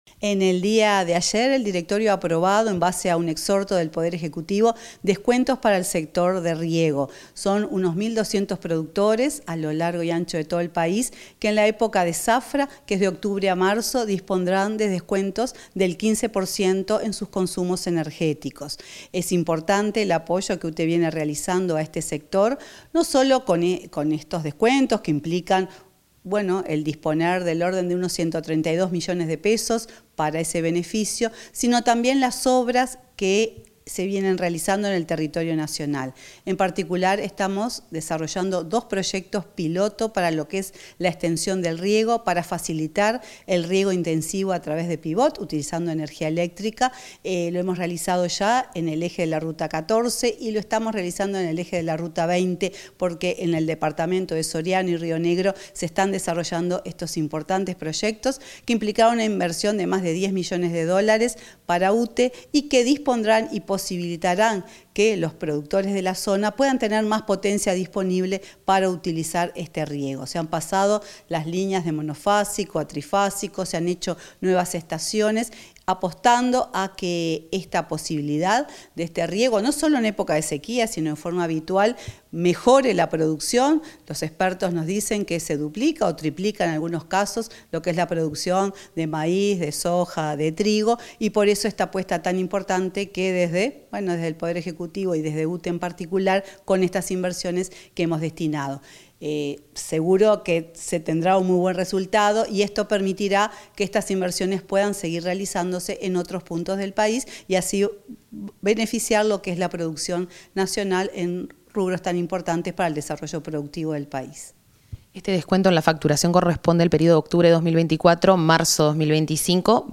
Entrevista a la presidenta de UTE, Silvia Emaldi, sobre exoneraciones a regantes y productores vitivinícolas